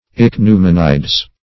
ichneumonides - definition of ichneumonides - synonyms, pronunciation, spelling from Free Dictionary
Search Result for " ichneumonides" : The Collaborative International Dictionary of English v.0.48: Ichneumonides \Ich`neu*mon"i*des\, n. pl.